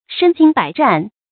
身经百战 shēn jīng bǎi zhàn 成语解释 身：亲身；百：极言其多；亲身经历过许多战斗。
成语繁体 身經百戰 成语简拼 sjbz 成语注音 ㄕㄣ ㄐㄧㄥ ㄅㄞˇ ㄓㄢˋ 常用程度 常用成语 感情色彩 中性成语 成语用法 主谓式；作谓语、定语；含褒义 成语结构 主谓式成语 产生年代 古代成语 成语正音 战，不能读作“zàn”。